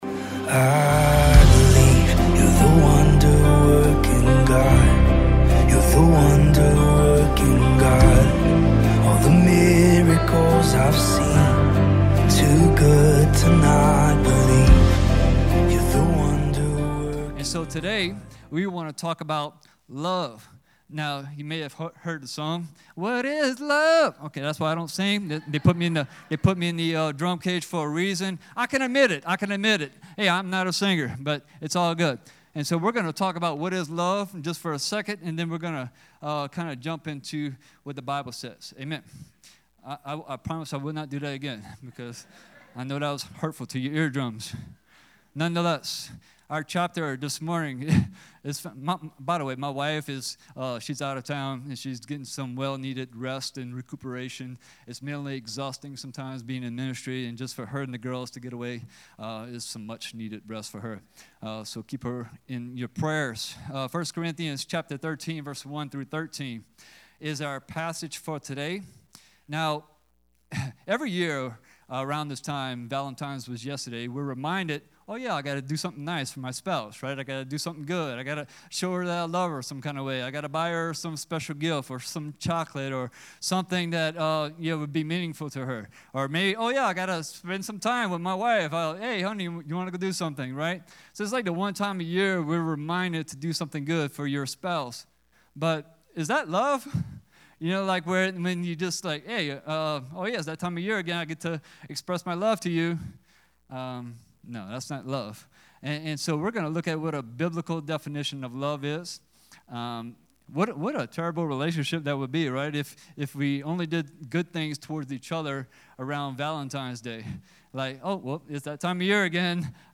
Sermons | Hope Community Church